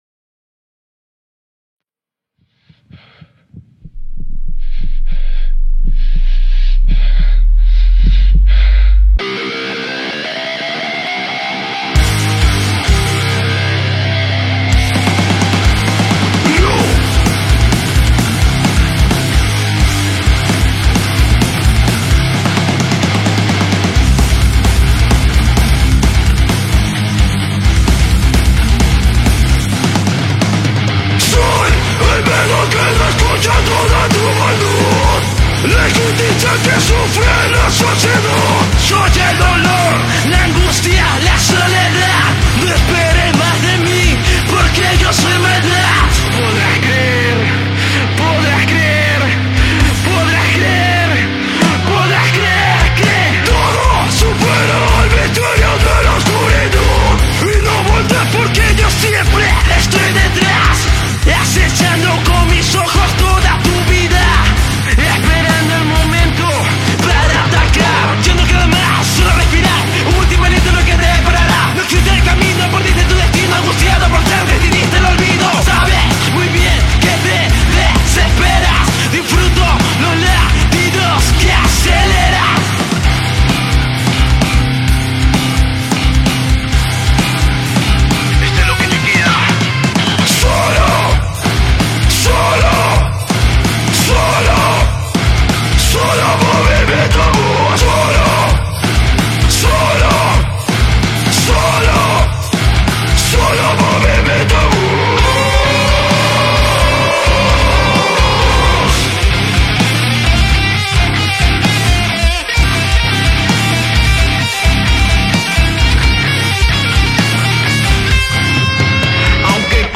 Género : NU METAL / METAL CORE / RAP CORE